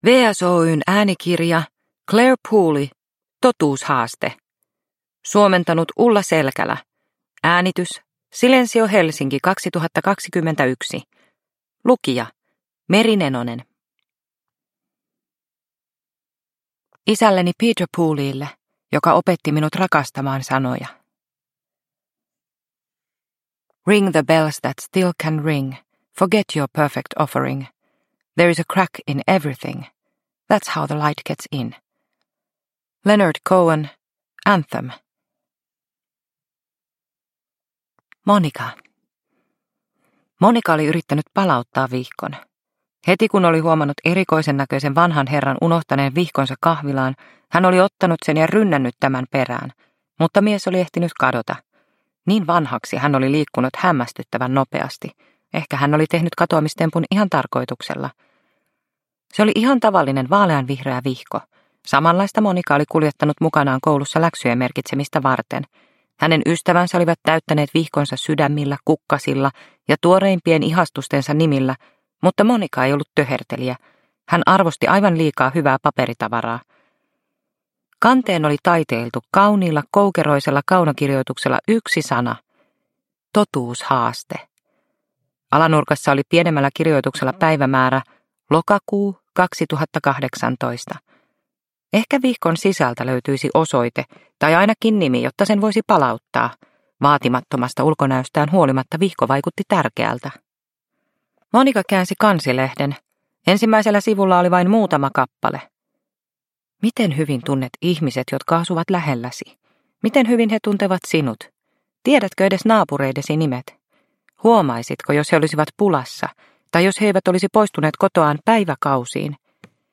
Totuushaaste – Ljudbok – Laddas ner